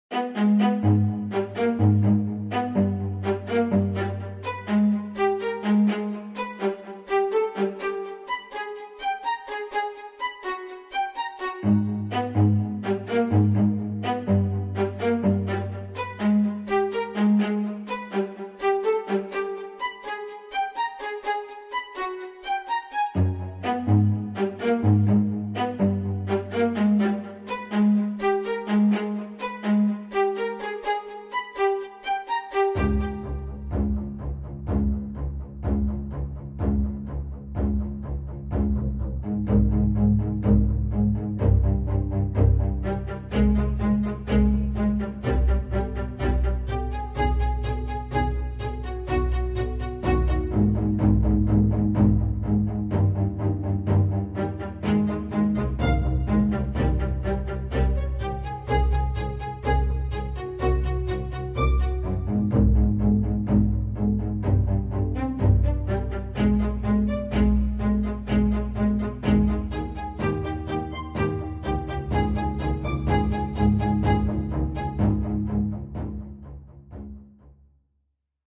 Download Drama sound effect for free.